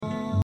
une quinte à la basse
juxtapose ces deux notes inconciliables